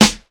snare035.wav